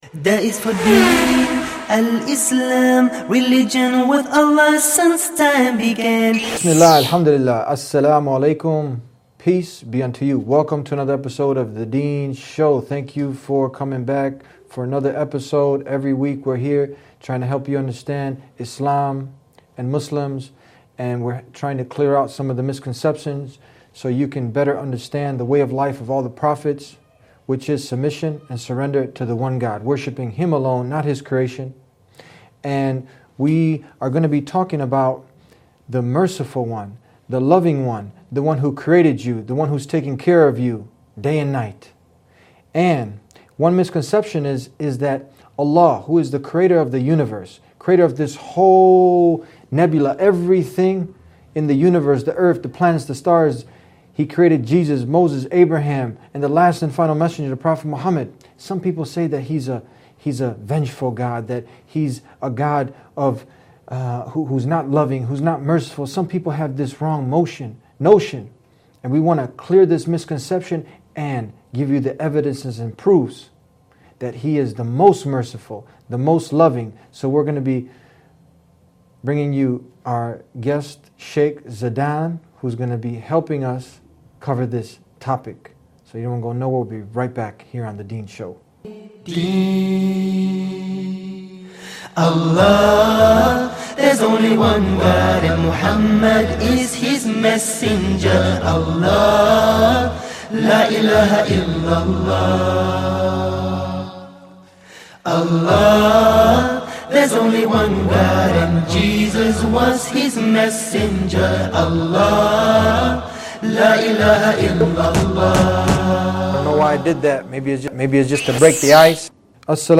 13650 views High Quality: Download (111.88 MB) Medium Quality: Download (50.97 MB) MP3 Audio (00:30:00): Download (18.4 MB) Transcript: Download (0.07 MB) Allah: The Most Merciful, The Most Loving Mercy is one the many beautiful attributes of God. God in many holy scriptures reminds us over and over his mercy. In this lecture